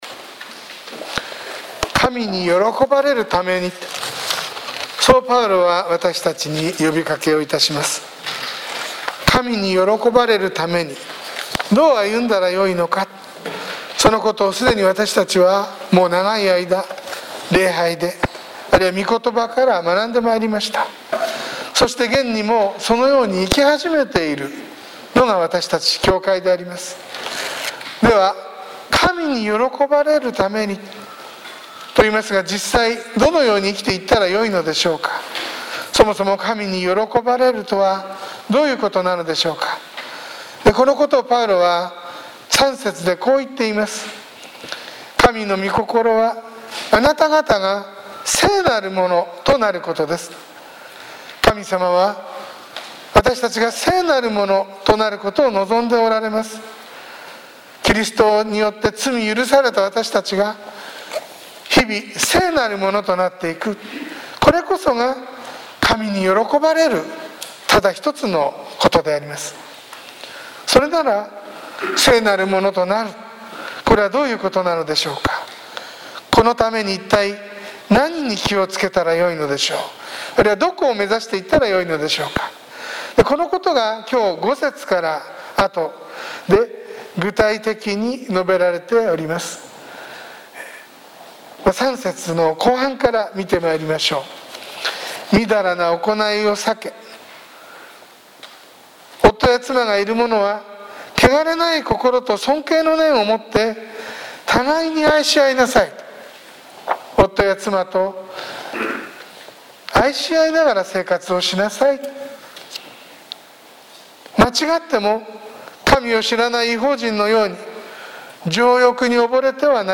sermon-2020-10-18